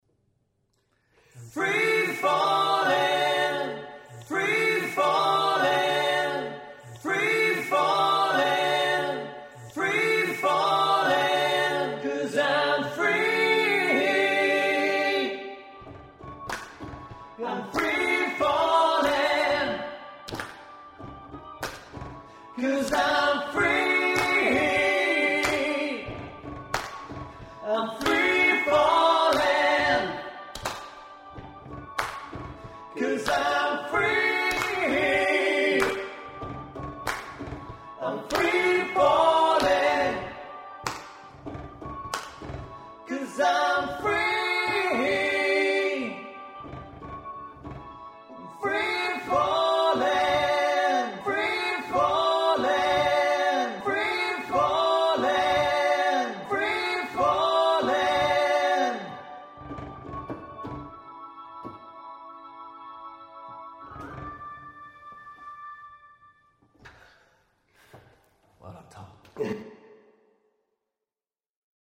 During a normal Loopy rehearsal news came through that Tom Petty had died.